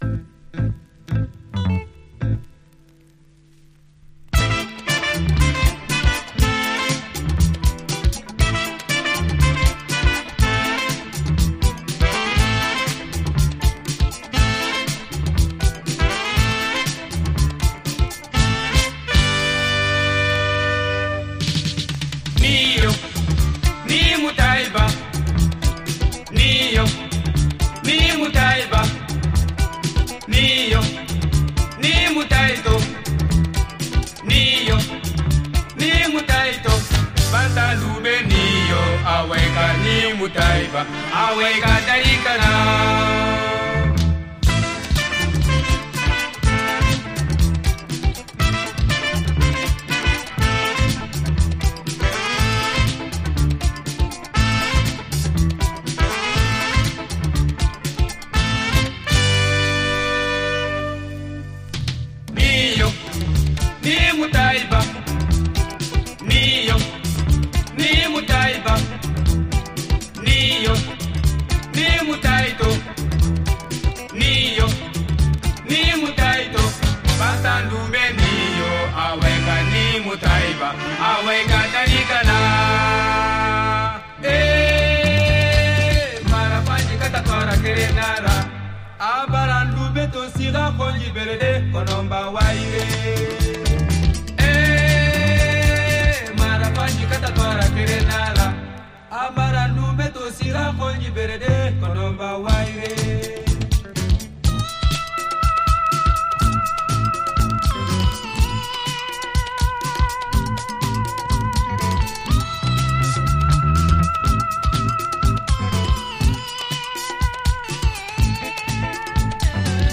Lead Guitar, Vocals
Bells
Tenor Saxophone
Chorus
Trumpet
Balafon
Drums
Rhythm Guitar
Organ
Bass Guitar
Congas